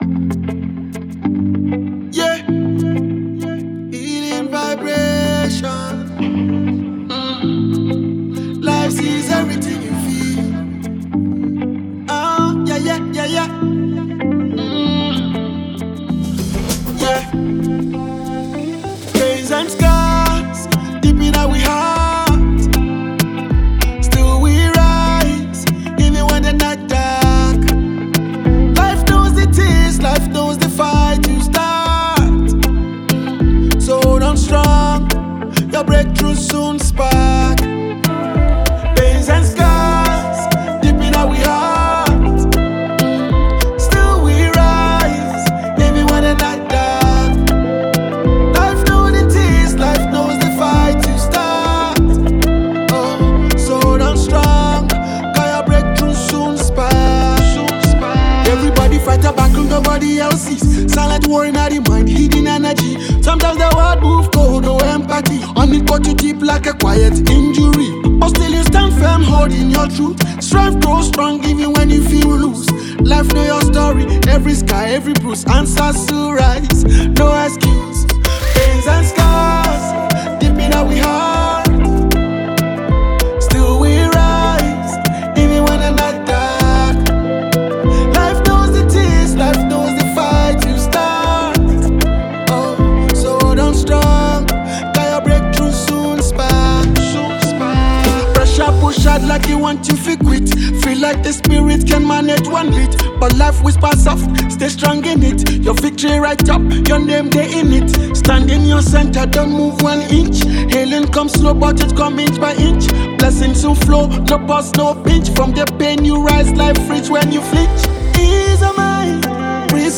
delivers with restraint and sincerity rather than theatrics